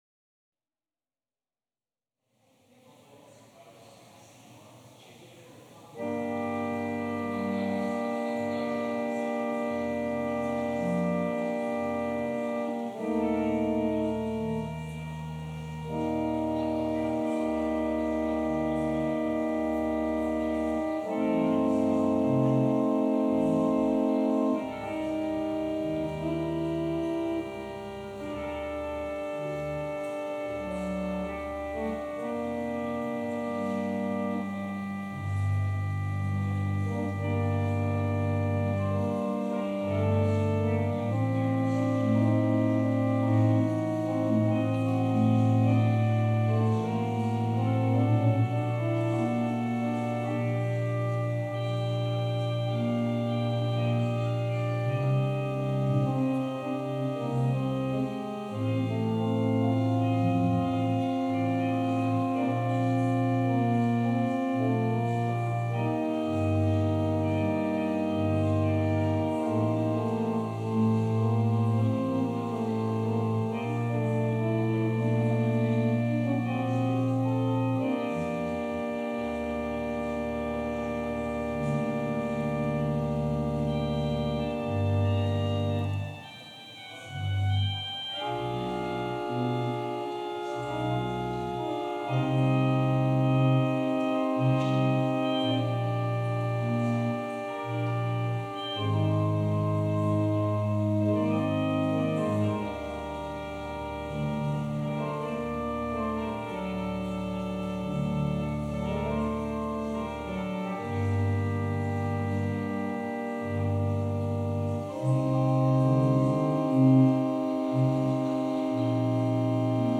Passage: Luke 8: 26-39 Service Type: Sunday Service Scriptures and sermon from St. John’s Presbyterian Church on Sunday